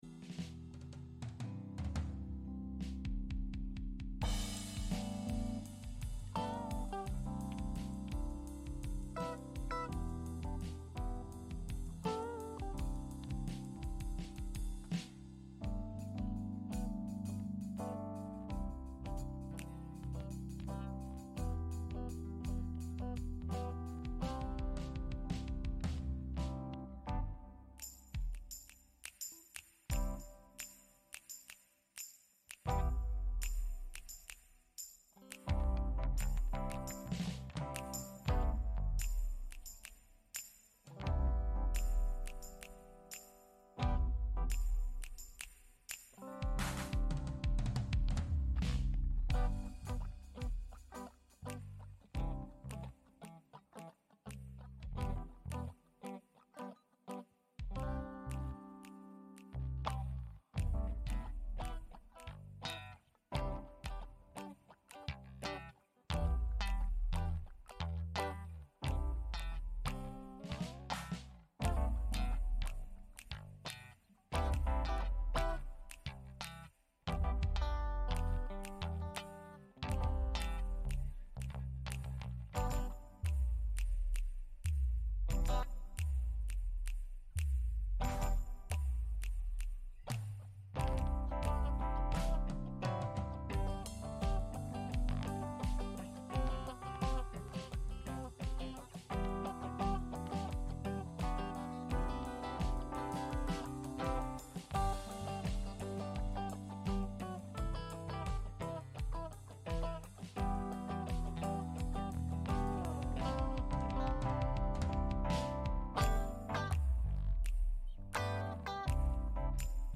Gottesdienst am 24. März 2024 aus der Christuskirche Altona